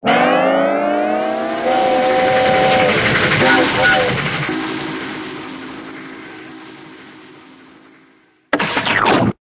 TL_train.ogg